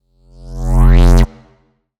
Magic Spell_Short Reverse_1.wav